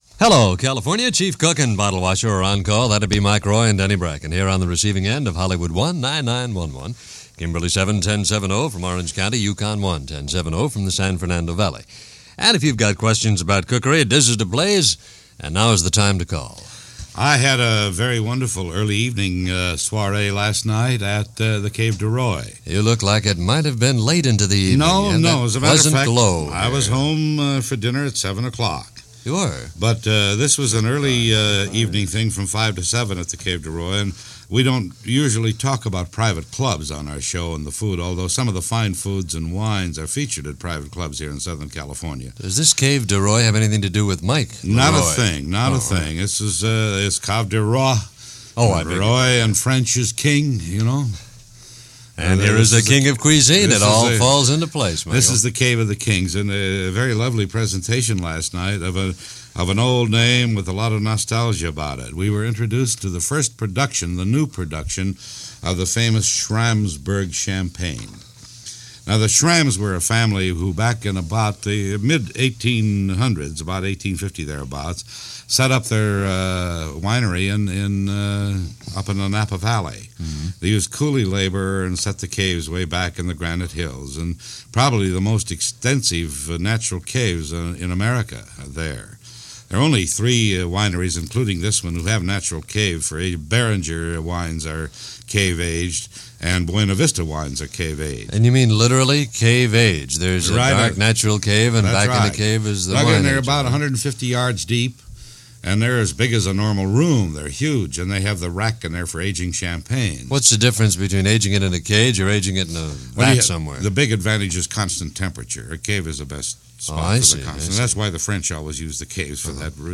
KNX-AM/FM Los Angeles